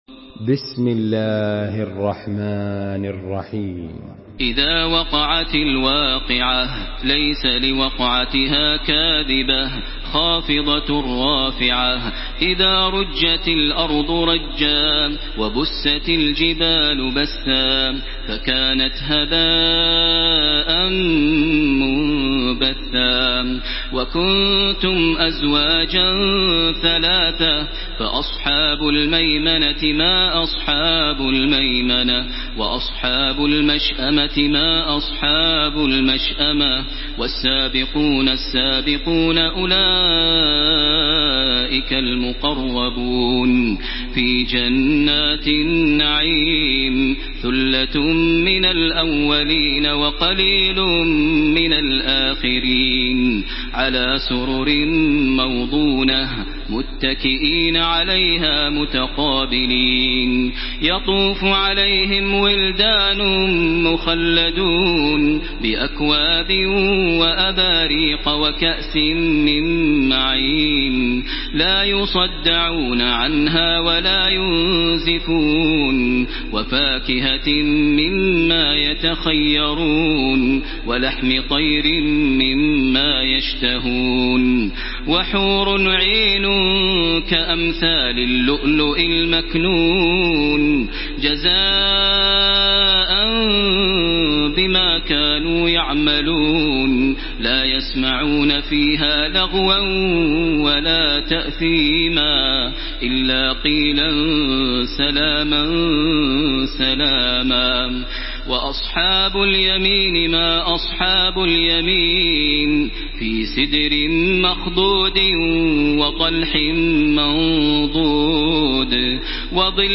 Surah Vakia MP3 by Makkah Taraweeh 1434 in Hafs An Asim narration.
Murattal